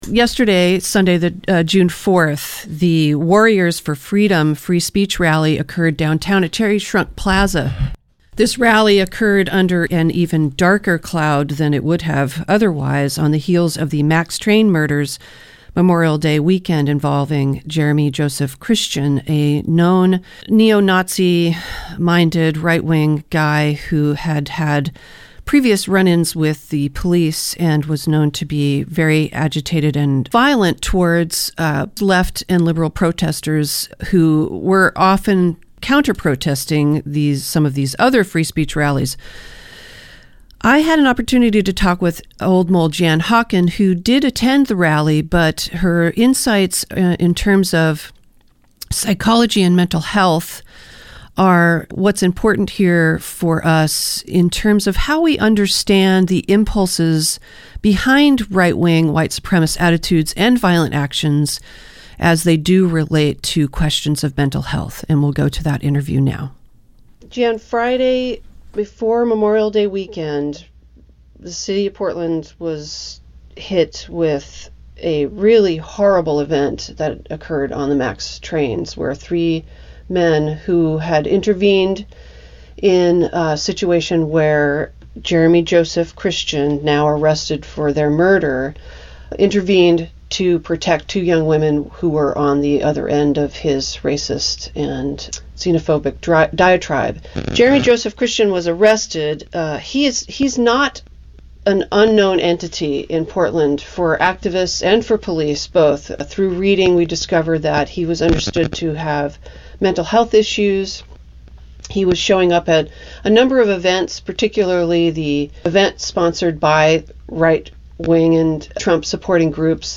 APPLYING MENTAL HEALTH CATEGORIES TO THE ALT-RIGHT In conversation